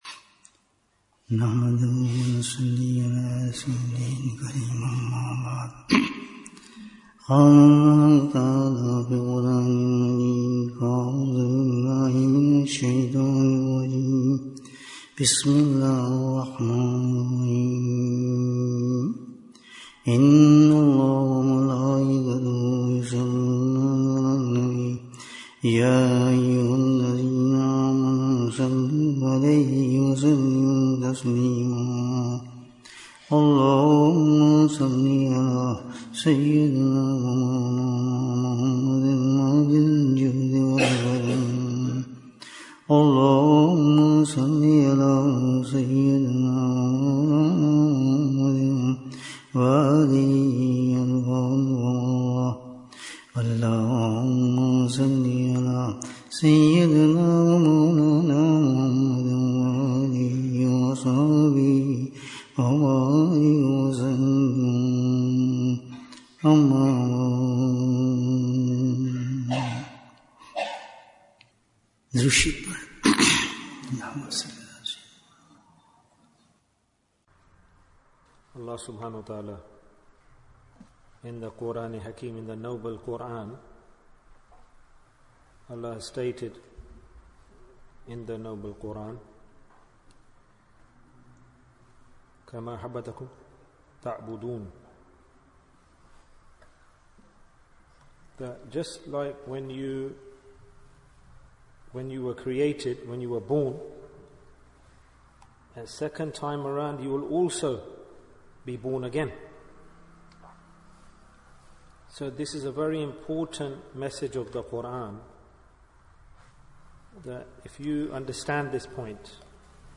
Message of Imam Hussain (ra) Bayan, 58 minutes18th July, 2024